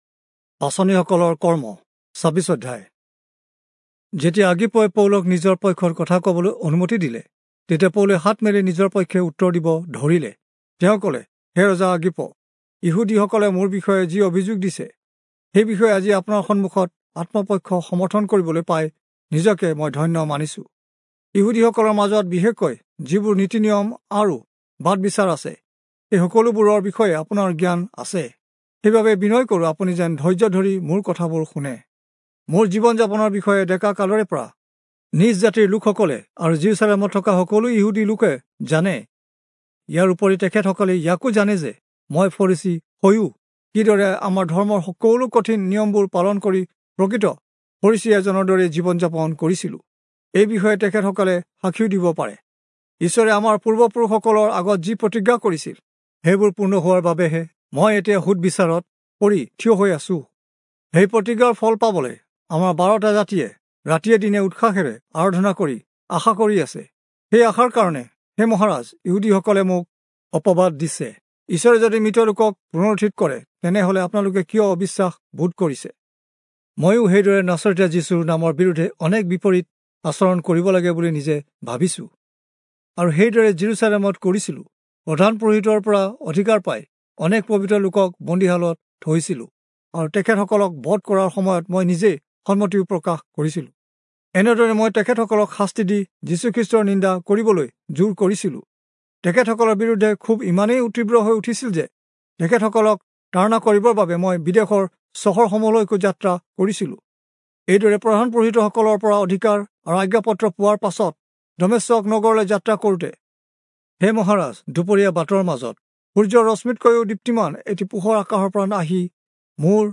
Assamese Audio Bible - Acts 25 in Irvte bible version